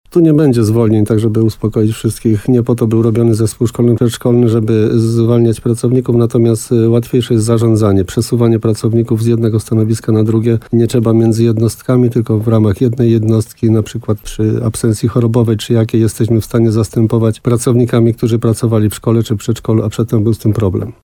– Nikt przez to nie stracił pracy – zaznaczał w programie Słowo za Słowo w RDN Nowy Sącz wójt gminy Rytro Jan Kotarba.